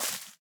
Minecraft Version Minecraft Version 1.21.5 Latest Release | Latest Snapshot 1.21.5 / assets / minecraft / sounds / block / azalea_leaves / break2.ogg Compare With Compare With Latest Release | Latest Snapshot